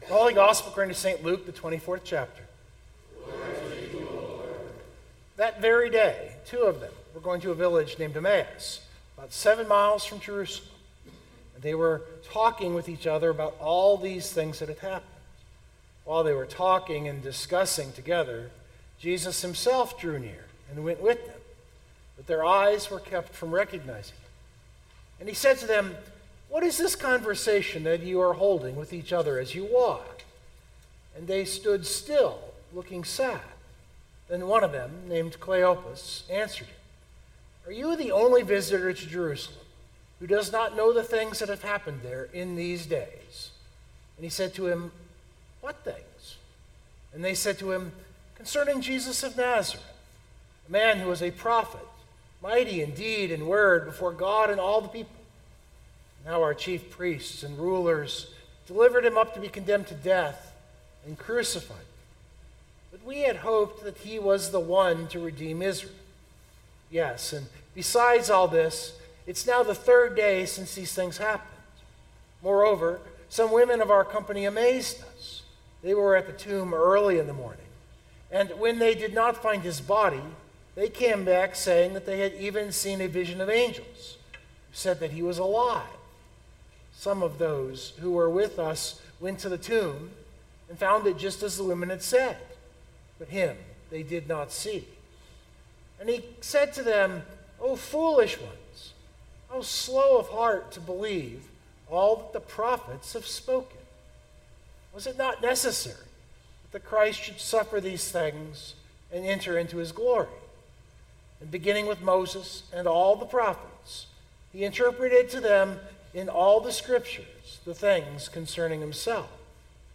041926 sermon Download Biblical Text: Luke 24:13-35 The Road to Emmaus is one of the most evocative stories in scripture.